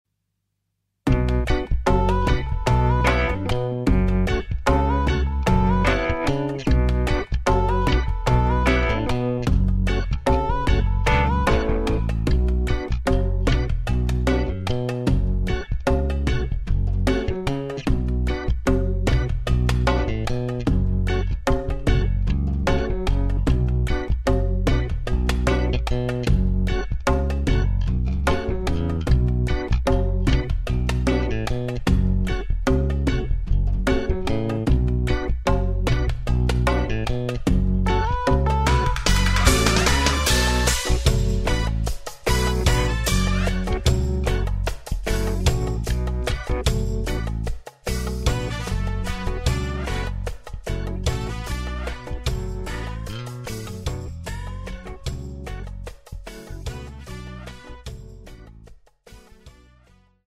歌曲调式：D大调
伴奏试听